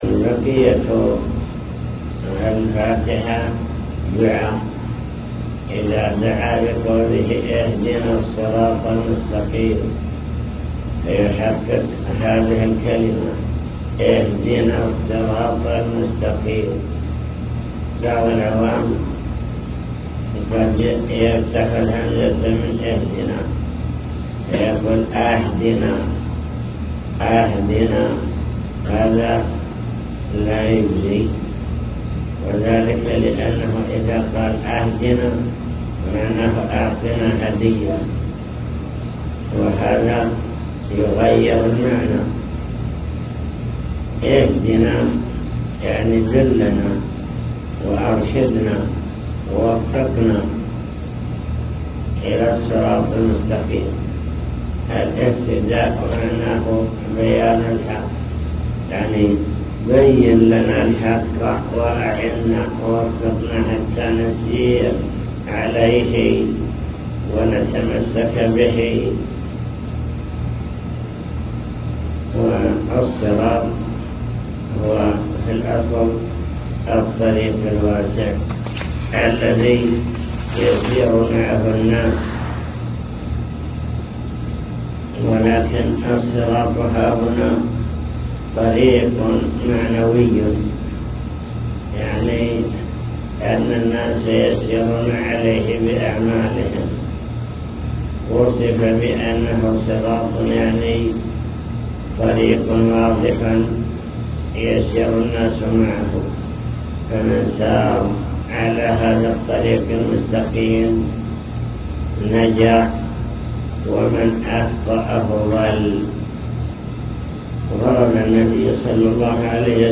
المكتبة الصوتية  تسجيلات - لقاءات  حول أركان الصلاة (لقاء مفتوح) من أركان الصلاة: قراءة الفاتحة